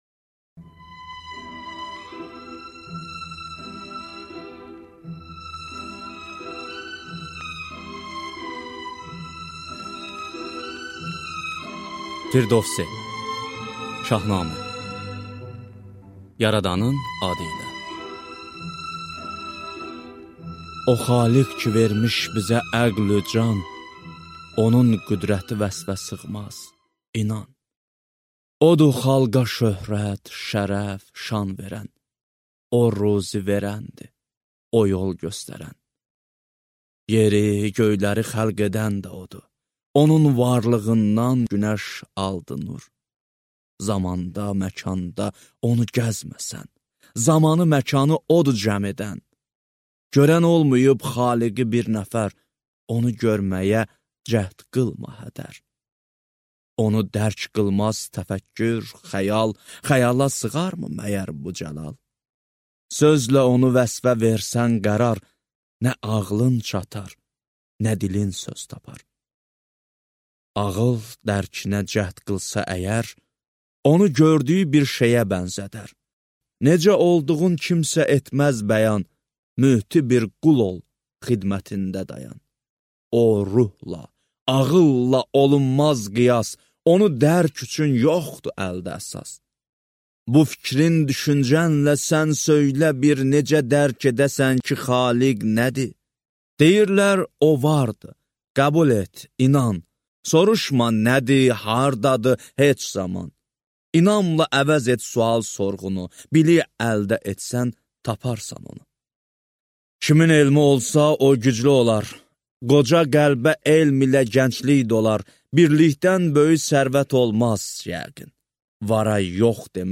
Аудиокнига Şahnamə | Библиотека аудиокниг